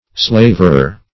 Slaverer \Slav"er*er\, n. A driveler; an idiot.